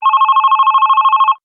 phone-incoming-call.wav